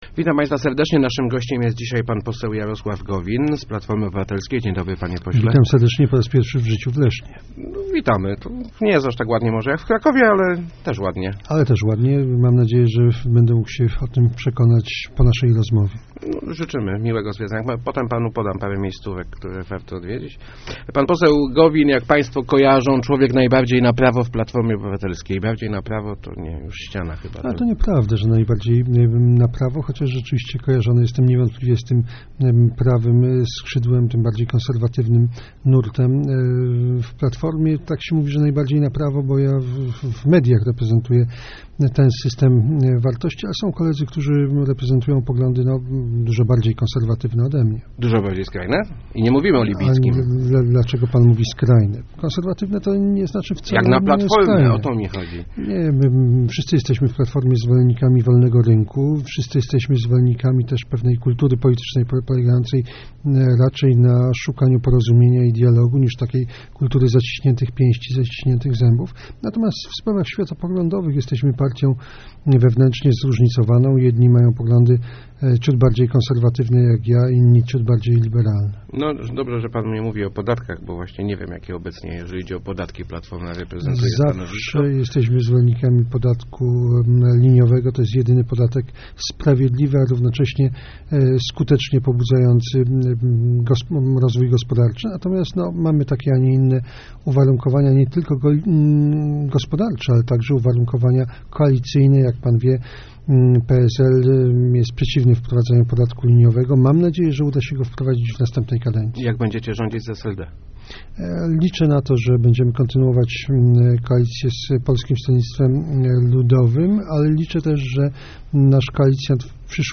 Jeżeli Polacy powierzą nam ster rządów na następne cztery lata, powinniśmy odważniej reformować państwo - mówił w Rozmowach Elki poseł PO Jarosław Gowin.